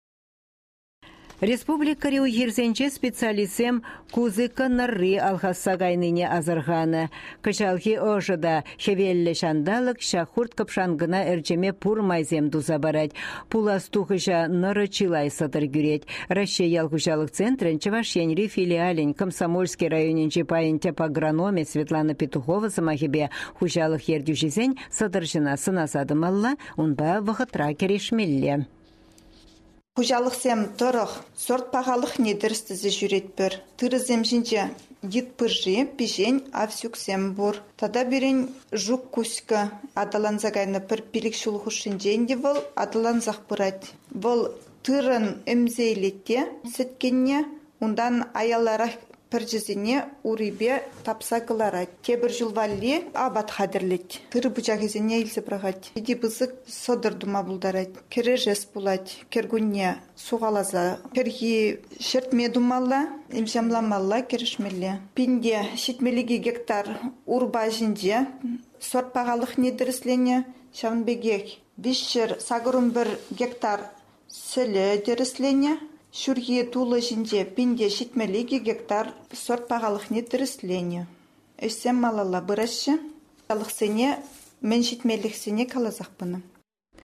Выступление